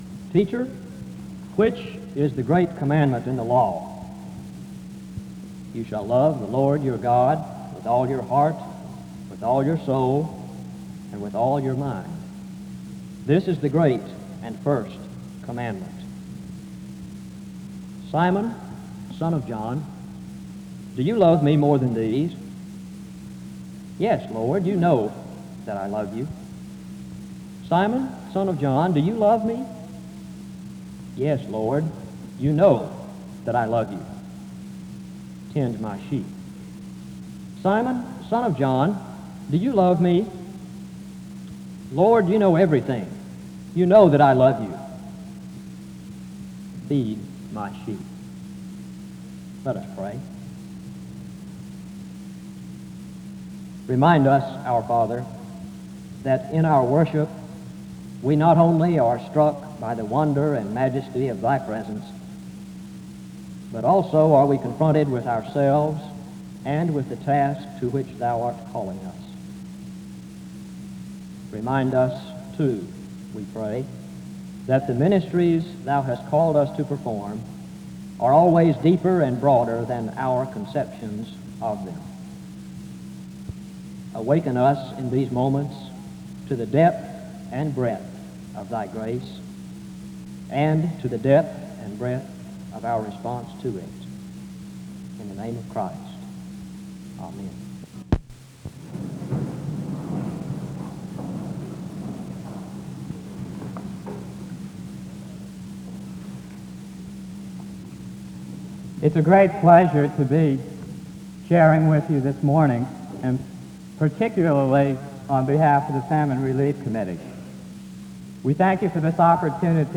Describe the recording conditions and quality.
The service starts with a scripture reading from 0:00-0:49. A prayer is offered from 0:52-1:37. An introduction to the speaker is given from 1:49-4:15. SEBTS Chapel and Special Event Recordings